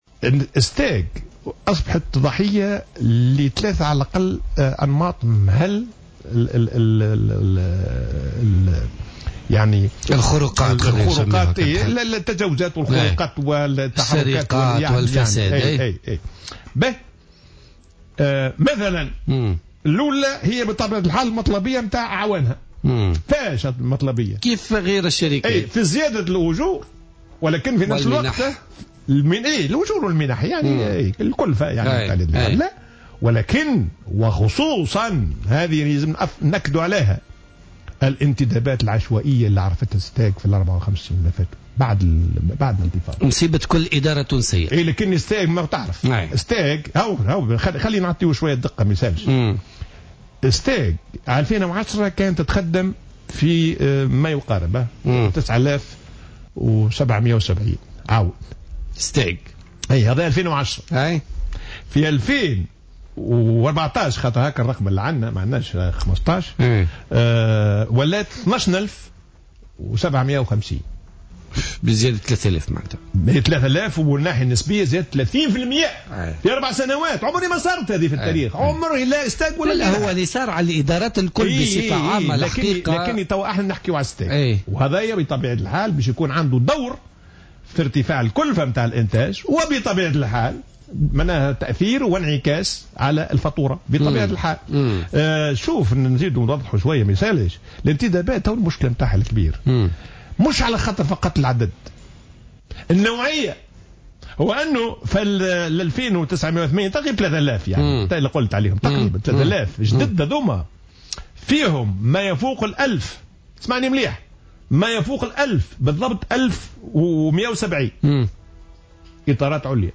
وقال الديماسي في مداخلة له اليوم في برنامج "بوليتيكا" إن عدد العاملين في "الستاغ" كان في حدود 9770 عونا وإطارا ليقفز هذا العدد سنة 2014 إلى حوالي 12750 عونا، مشيرا إلى أن هذه الانتدابات تشمل عددا لا بأس به من الإطارات العليا والتي يتقاضى البعض منهم أحيانا أجورا تفوق أجور الوزراء.